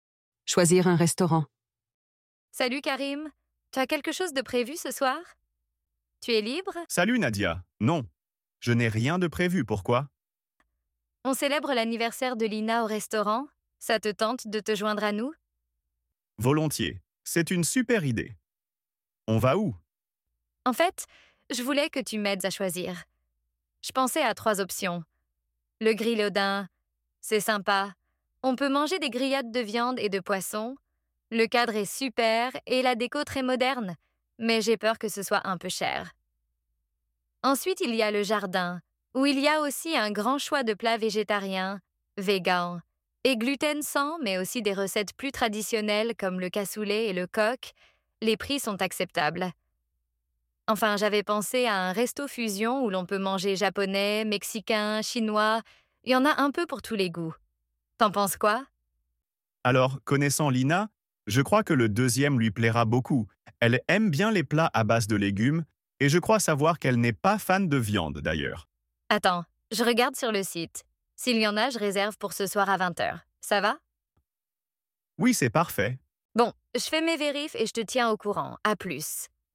Dialogues en Français